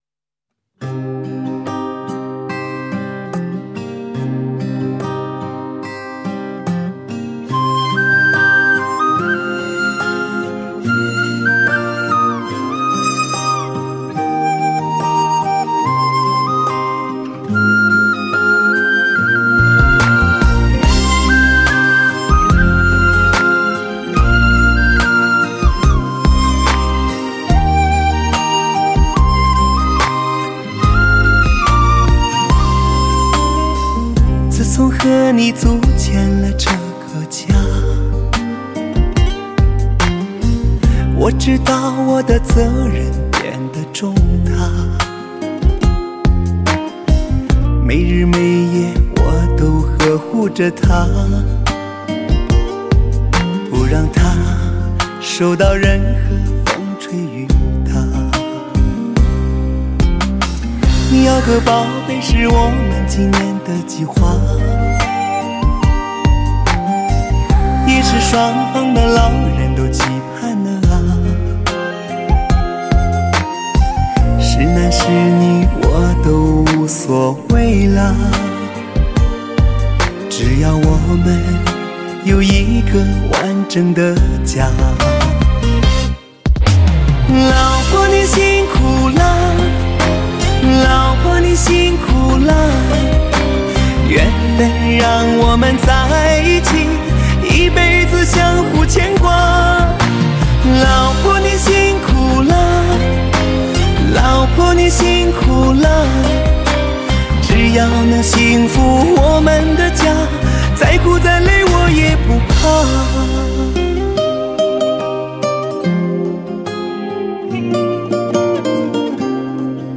特邀海峡两岸实力女歌手倾情对唱，呕心打造催泪情歌